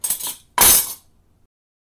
Descarga de Sonidos mp3 Gratis: tirar tenedor.
slam-fork-down.mp3